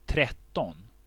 Ääntäminen
IPA: /ˈtrɛˌtɔn/